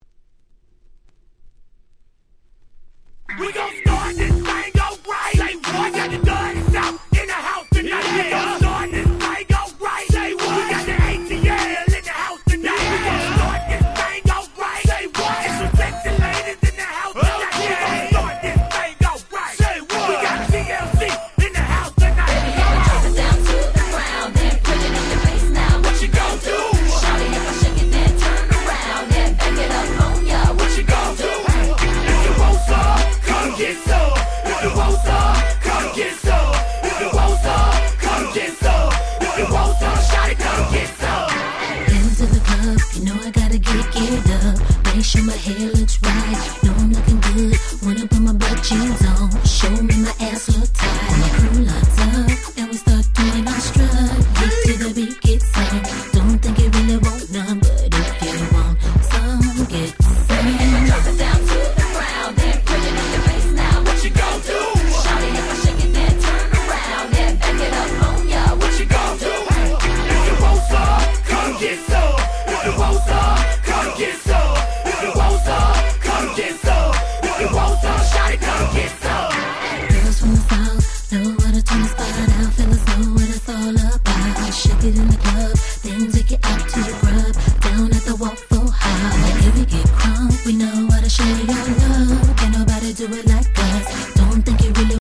03' Smash Hit R&B !!